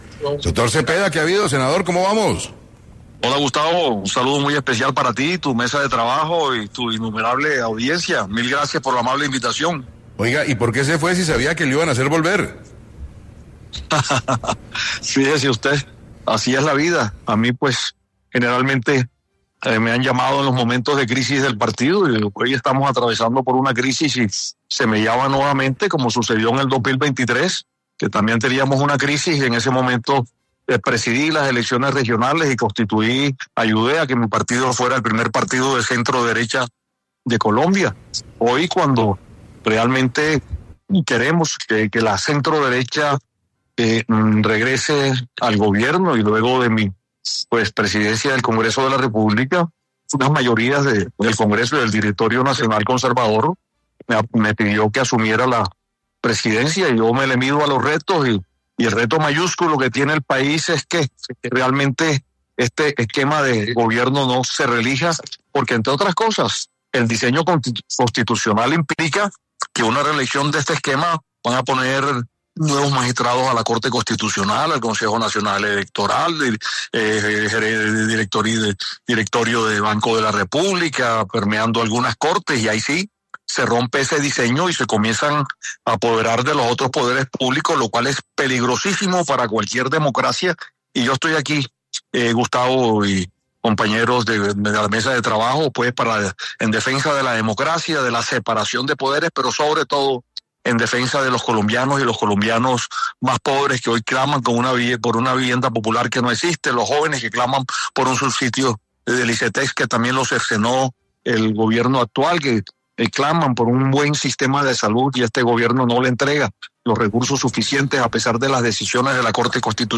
Cepeda dijo en 6AM de Caracol Radio que generalmente lo han llamado en los momentos de crisis del partido y ahora lo vuelven a llamar, tal como pasó en 2023 cuando ayudó a que su partido fuera el primero de centro derecha de Colombia.